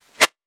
weapon_bullet_flyby_09.wav